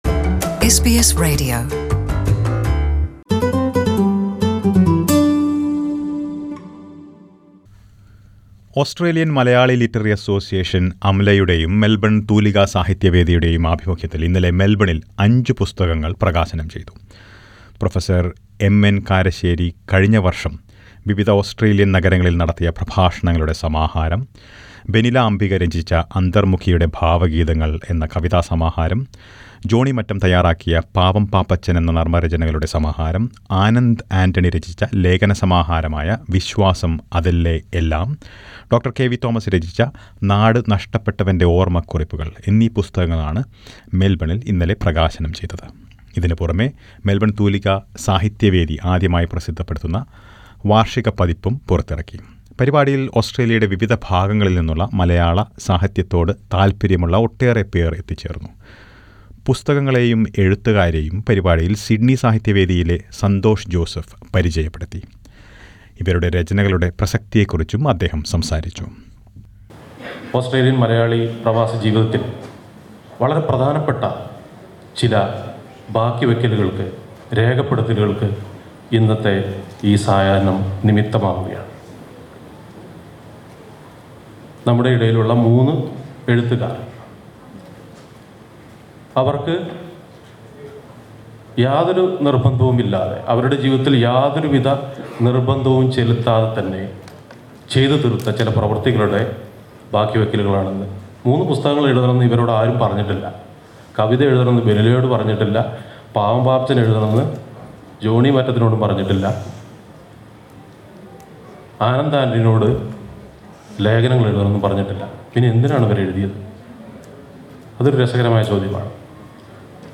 Listen to a report about the book release.